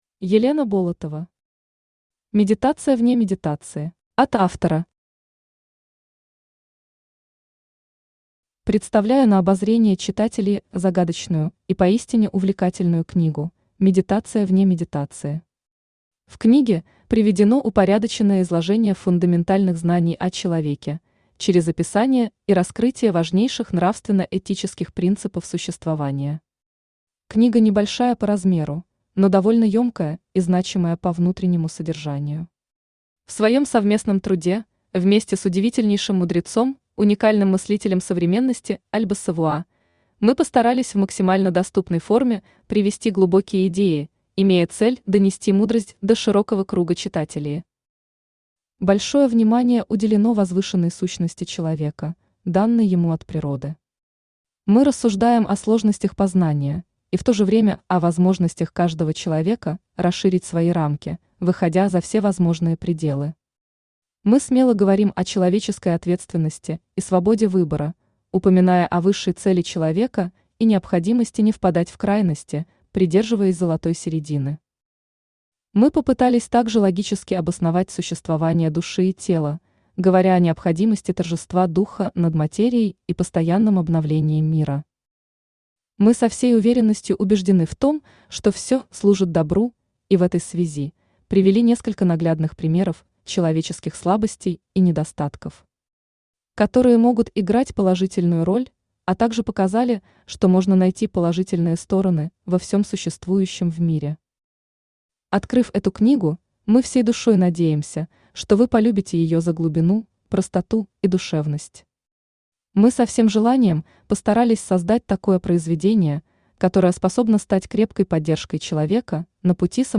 Aудиокнига Медитация вне медитации Автор Мата Сури Читает аудиокнигу Авточтец ЛитРес.